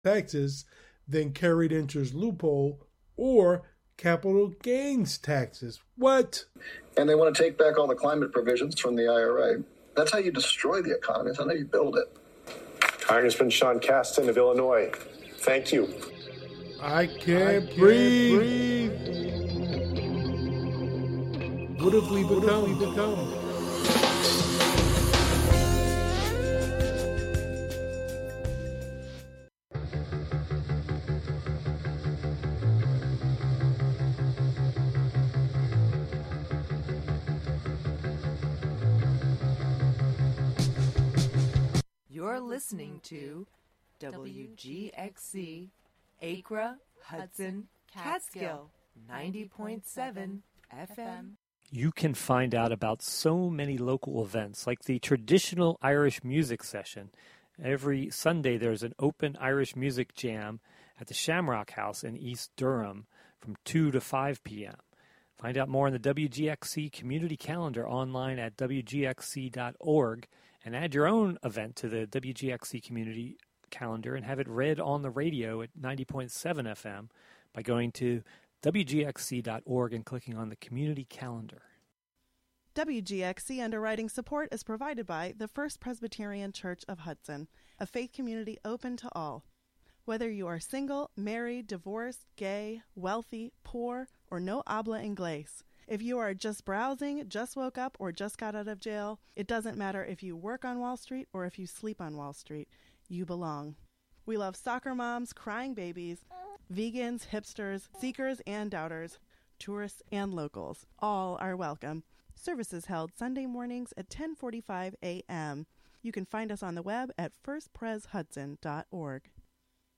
"The Irish Show" features the best of Irish music, sports results, requests, and interviews.